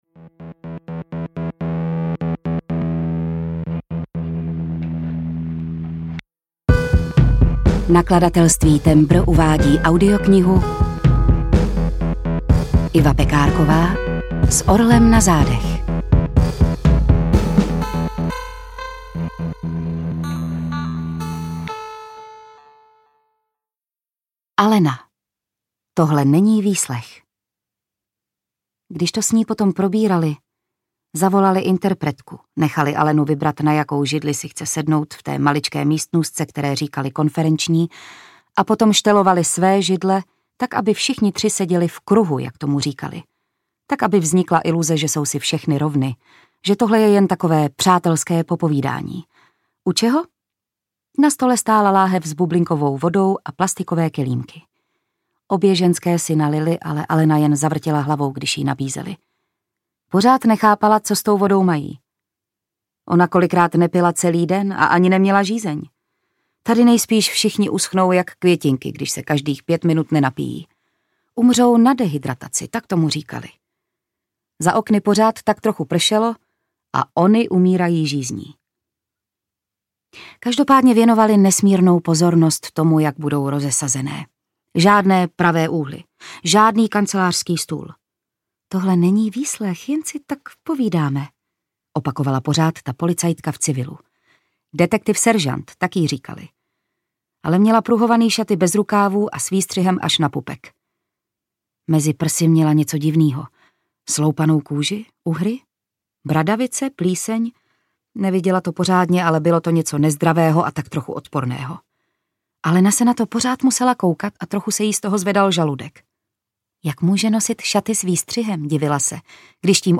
S orlem na zádech audiokniha
Ukázka z knihy
• InterpretJitka Ježková
s-orlem-na-zadech-audiokniha